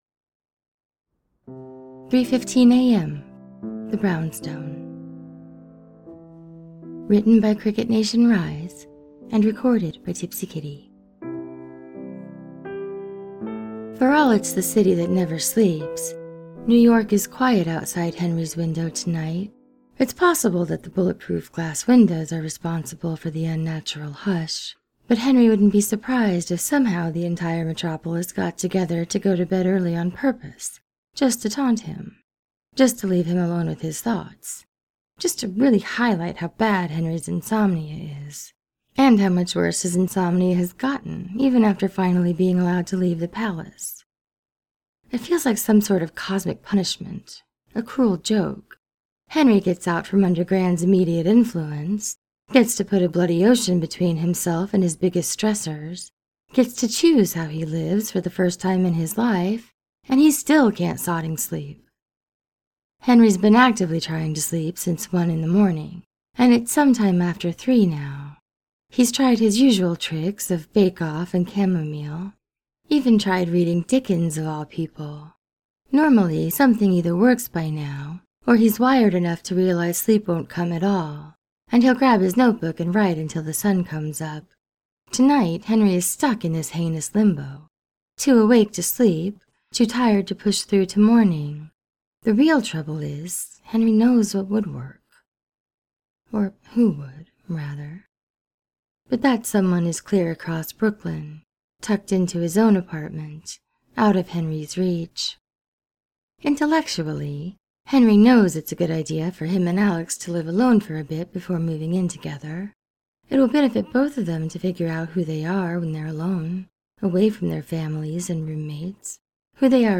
with music: